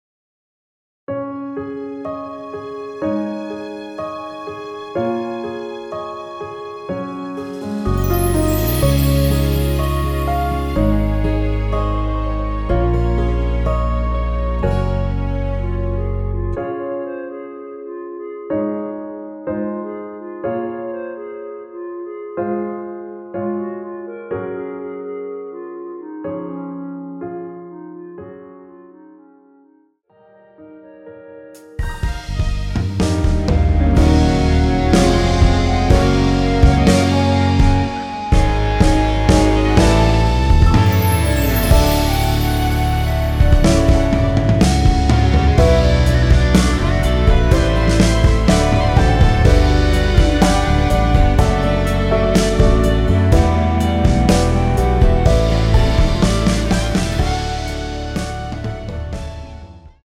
원키에서(-4)내린 멜로디 포함된 MR입니다.
Db
앞부분30초, 뒷부분30초씩 편집해서 올려 드리고 있습니다.
중간에 음이 끈어지고 다시 나오는 이유는